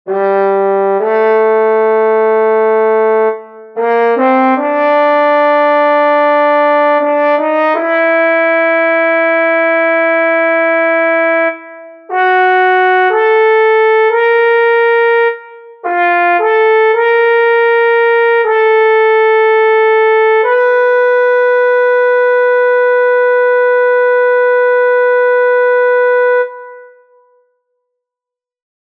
Key written in: F# Major
Type: Barbershop
Each recording below is single part only.